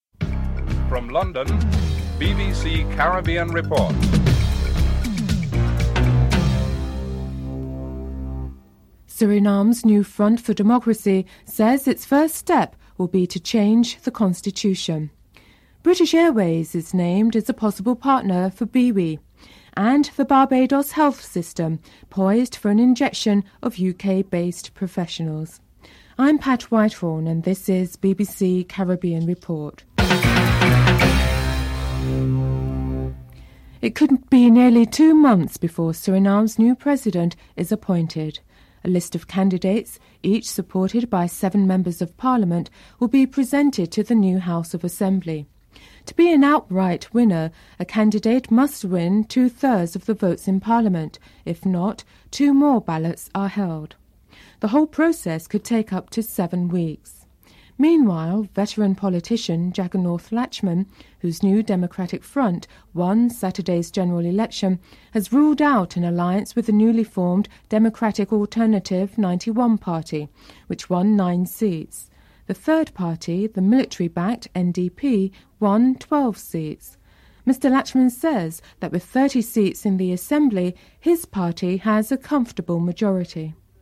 1. Headlines (00:00-00:32)
During a meeting in London, he outlines the benefits that the Barbados government is offering and comments from the audience are featured (08:26-10:26)